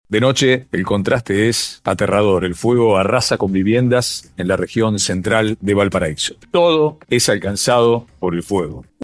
De esa forma describía un reportero de Telefé (Argentina) lo que es una de las mayores catástrofes en la historia reciente de Chile, que tuvo a la región de Valparaíso como protagonista.